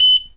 beep_03.wav